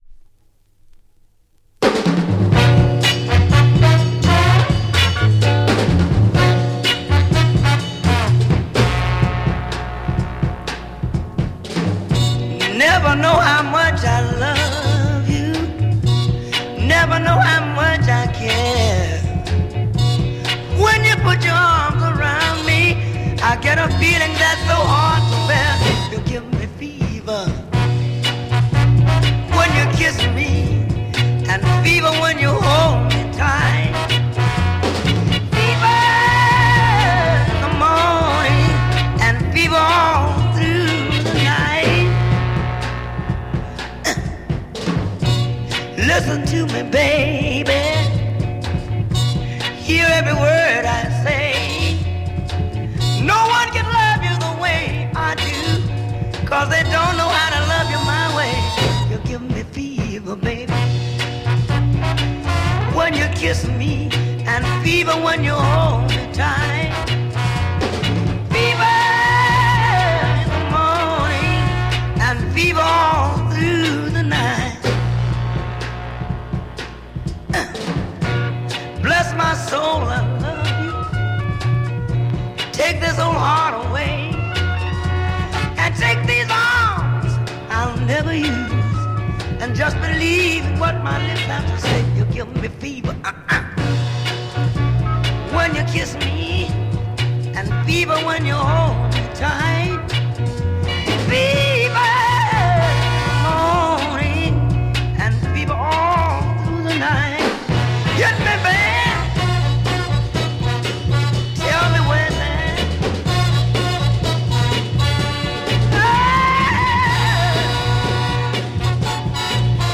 Classic Soul funk
Classic soul funk single, come with the French pic.